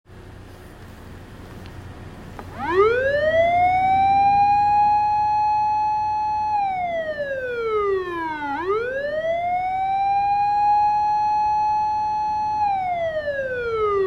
火災以外の出動時
「ウ～・ウ～・ウ～」
サイレン音（火災以外） （mp3） (音声ファイル: 224.9KB)
救助や警戒・救急支援など火災以外の災害現場に行くときは、「ウ～・ウ～・ウ～」というサイレン音だけを鳴らして走行します。